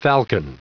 Prononciation du mot falcon en anglais (fichier audio)
Prononciation du mot : falcon